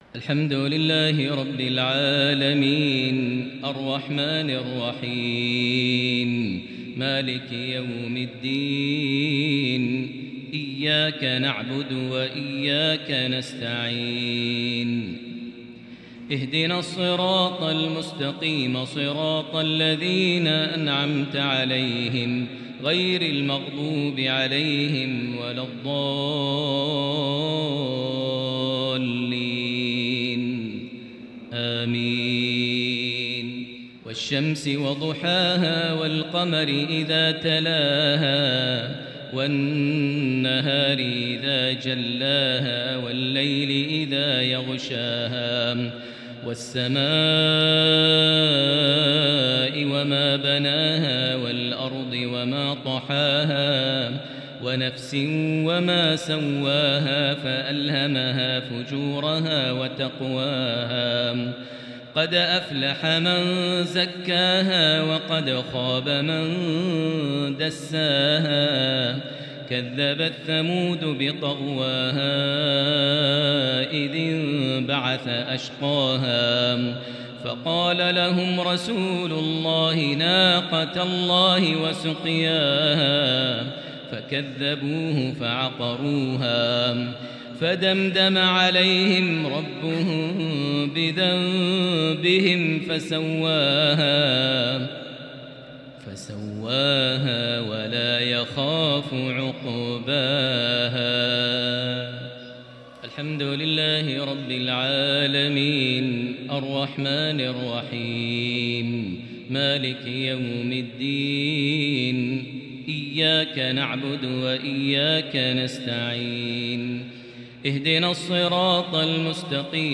صلاة الجمعة 9 ذو الحجة 1443هـ سورتي الشمس - الليل | salah_jumua_prayer from 8-7-2022 Surah Ash-Shams + Surah Al-Lail > 1443 🕋 > الفروض - تلاوات الحرمين